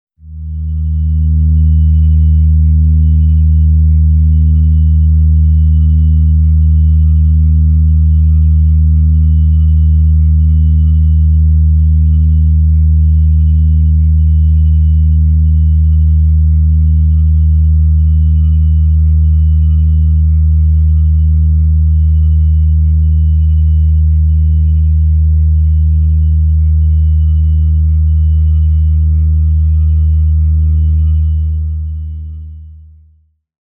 Royalty free music elements: Tones
mf_SE-8930-organ_tone_d_sharp.mp3